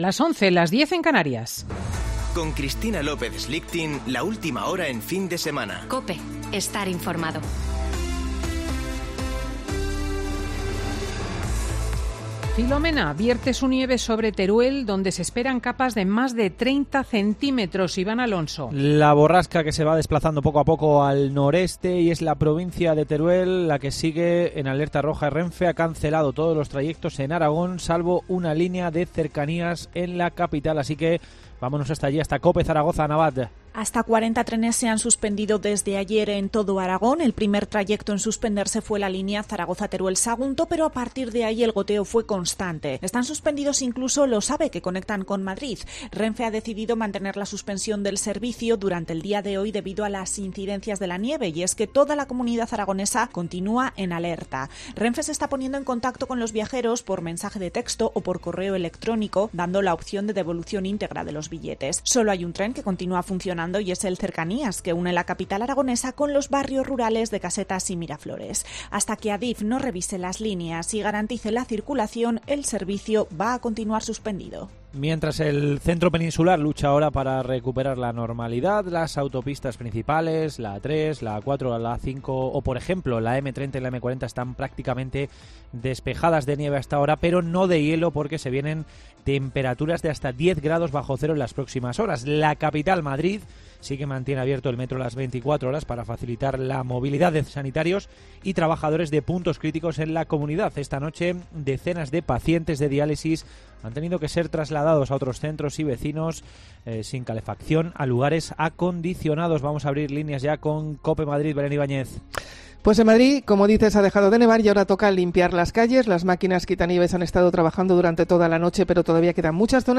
AUDIO: Boletín de noticias COPE del 10 de enero de 2021 a las 11.00 horas